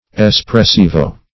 Espressivo \Es`pres*si"vo\